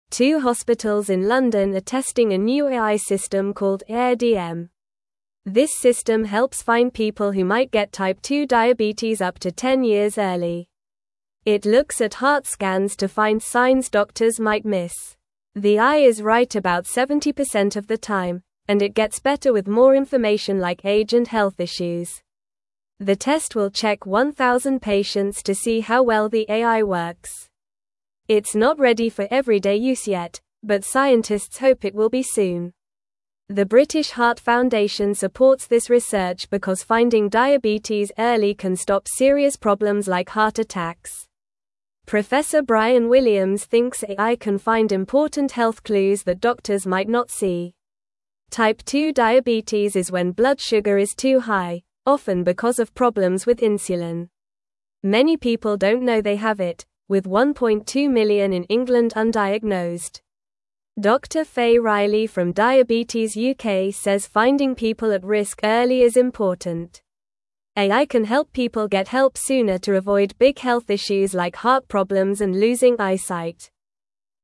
Normal
English-Newsroom-Lower-Intermediate-NORMAL-Reading-New-Computer-Helps-Find-Diabetes-Early-in-People.mp3